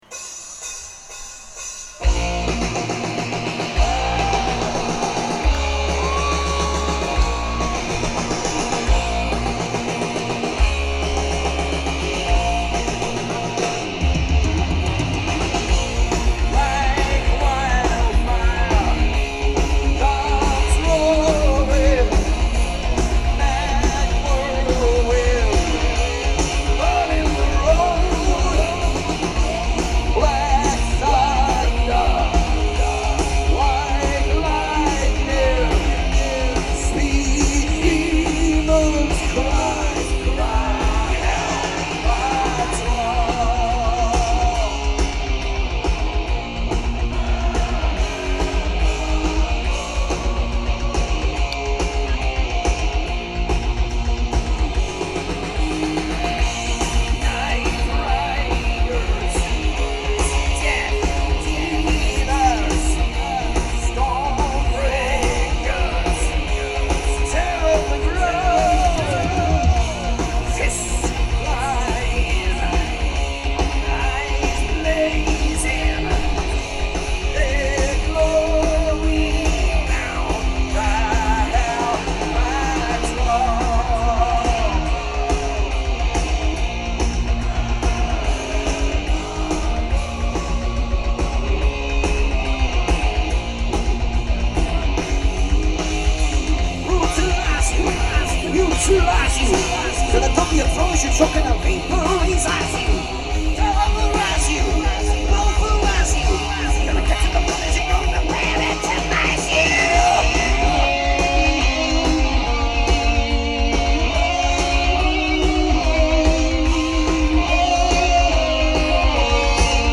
quality is very good.